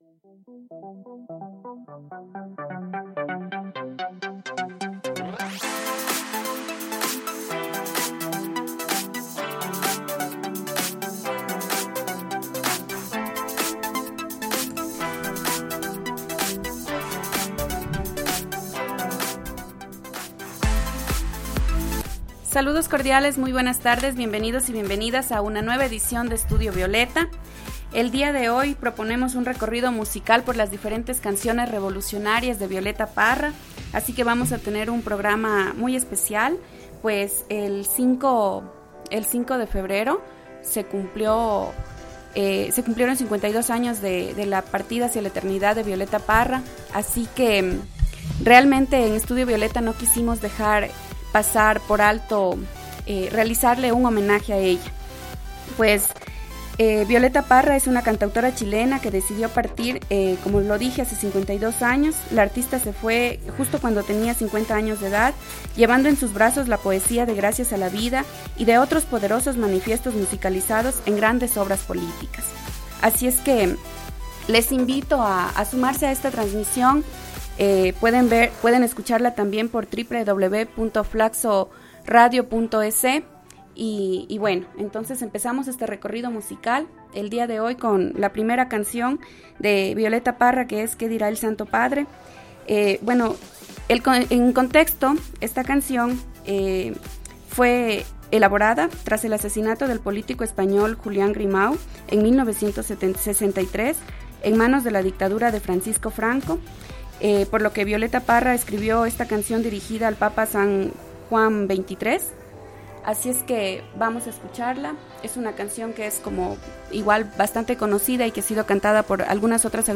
recorridos musicales semanales
canciones más revolucionarias y de sentido social